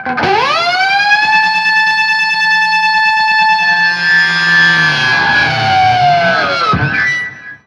DIVEBOMB12-R.wav